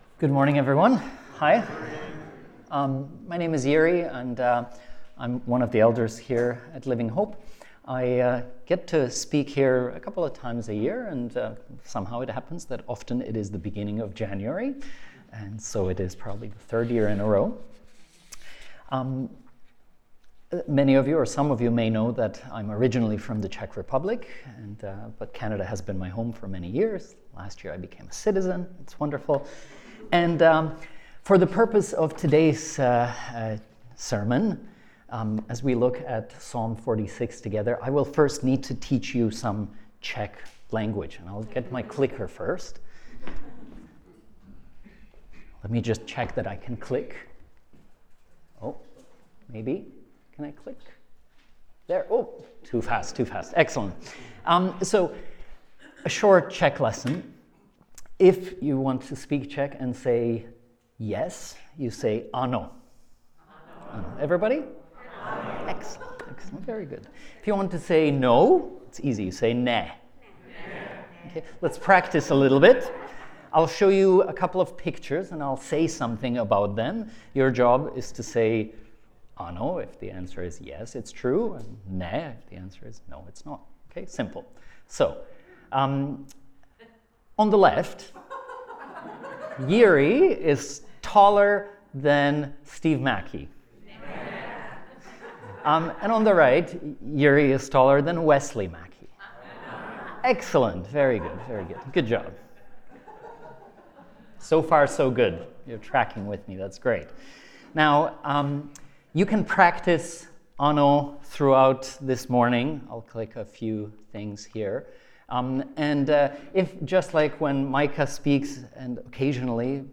New Year Sermon